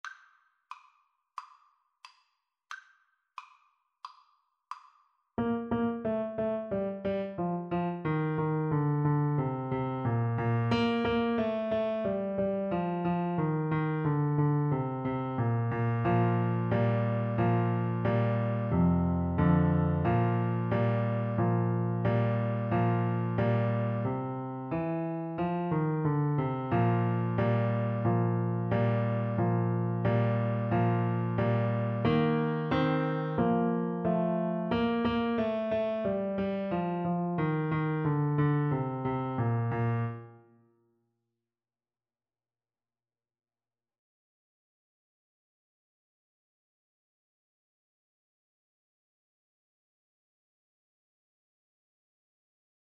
A beginners piece with a rock-like descending bass line.
March-like = 90
Pop (View more Pop Saxophone Music)